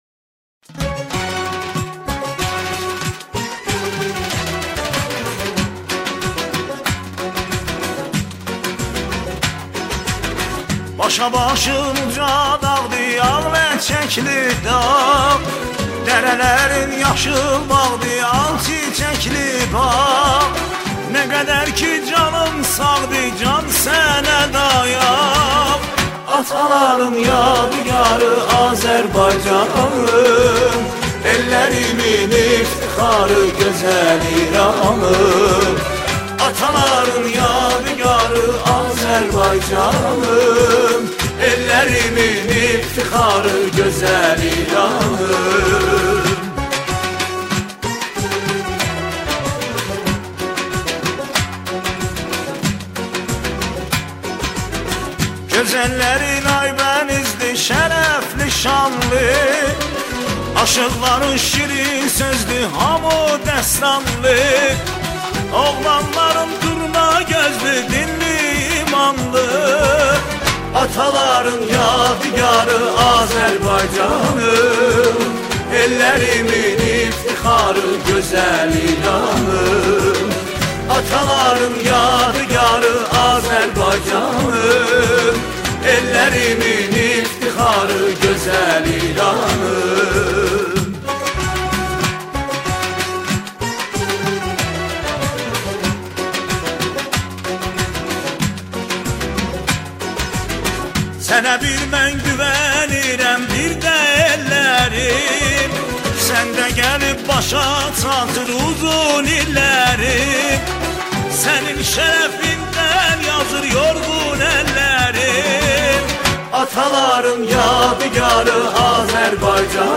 سرودهای شهرها و استانها